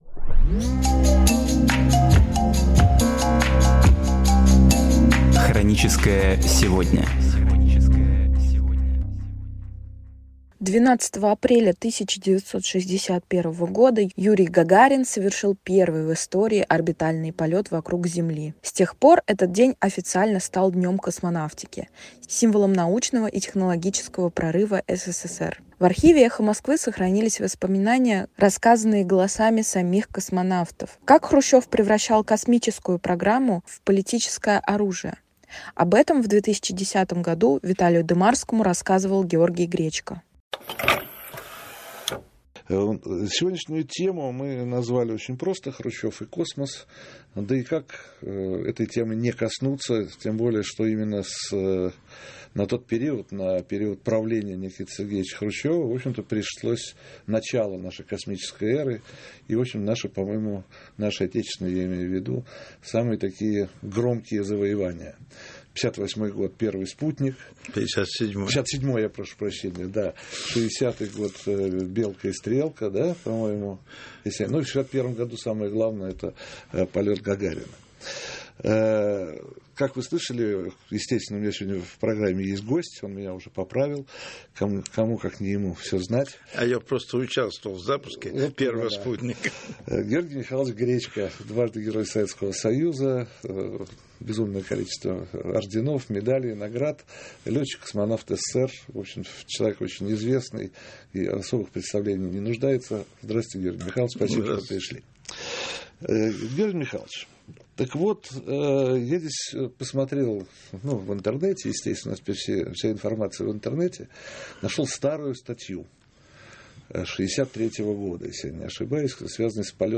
Архивные передачи «Эха Москвы» на самые важные темы дня сегодняшнего
Гости: Георгий Гречко, Александр Лазуткин
В архиве «Эха Москвы» сохранились воспоминания голосами самих космонавтов. Как Хрущёв превращал космическую программу в политическое оружие?